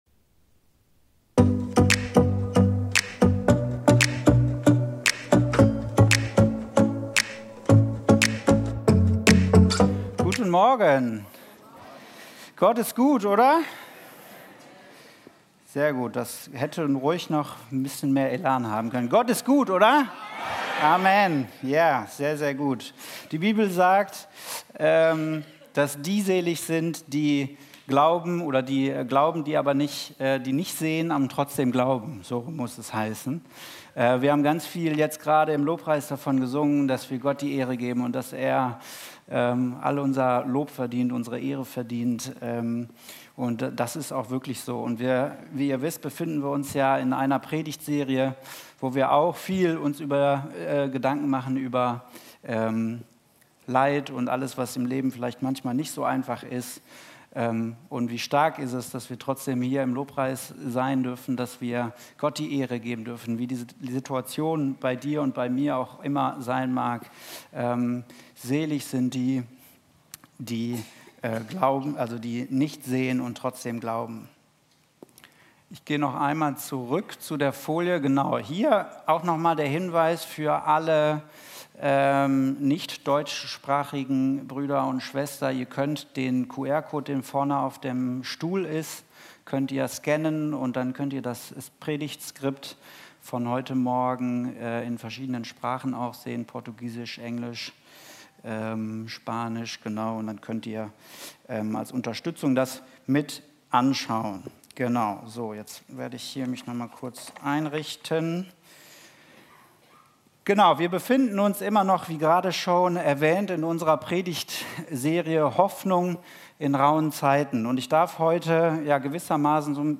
Live-Gottesdienst aus der Life Kirche Langenfeld.
Kategorie: Sonntaggottesdienst Predigtserie: Hoffnung in rauen Zeiten